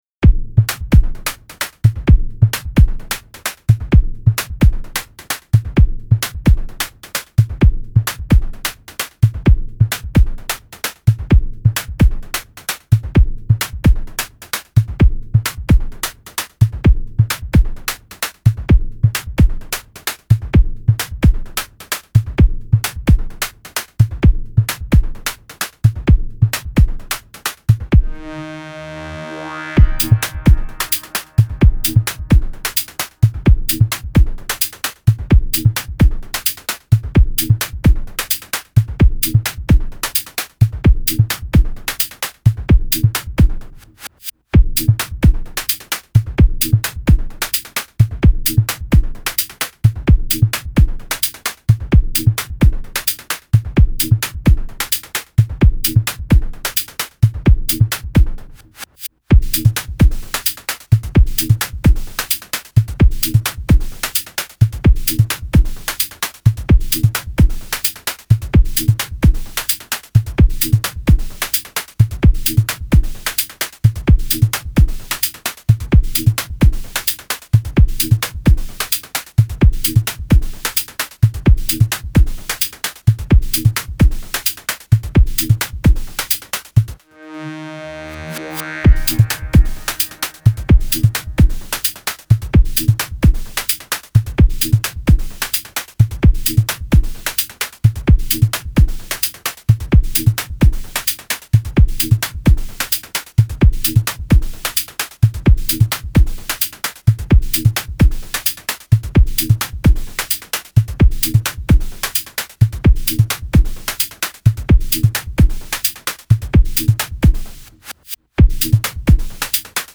瞑想っぽい静かなエレクトロ。